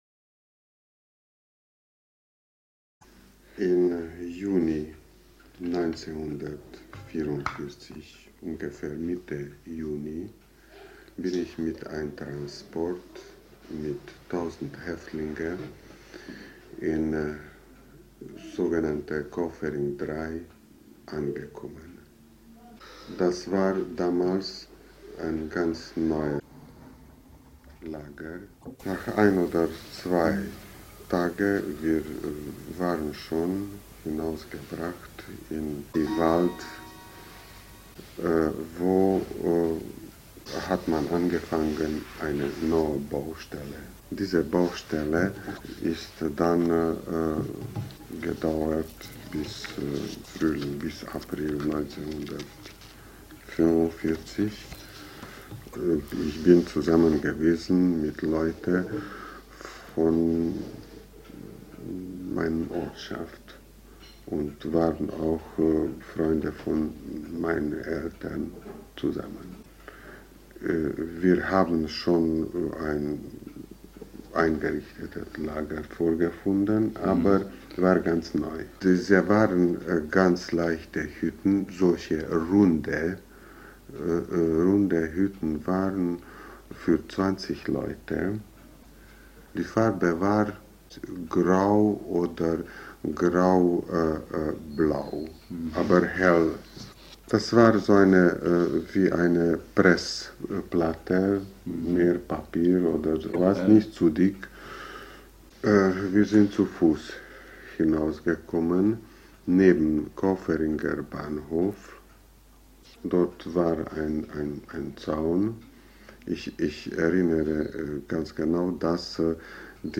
Tondokument